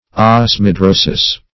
Search Result for " osmidrosis" : The Collaborative International Dictionary of English v.0.48: Osmidrosis \Os`mi*dro"sis\, n. [NL., from Gr.